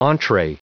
Prononciation du mot entree en anglais (fichier audio)
Prononciation du mot : entree